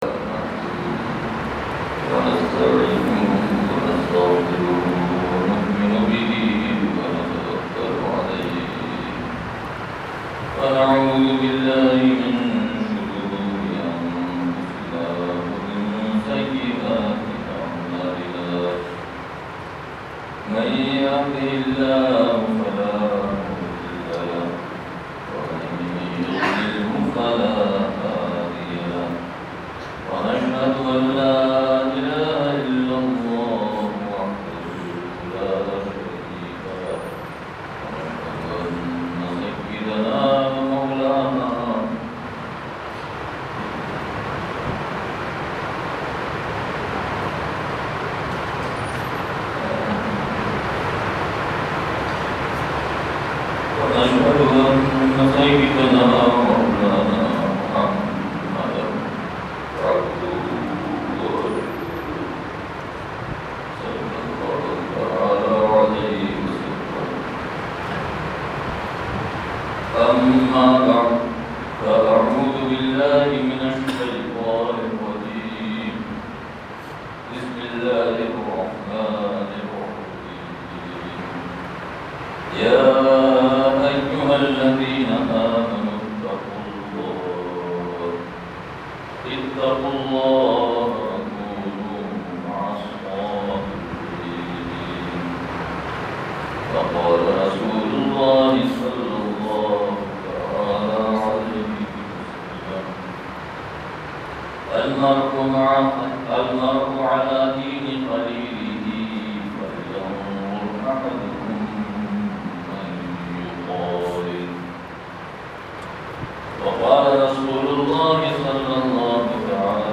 بیان – مسجد اختر سخرہ سوات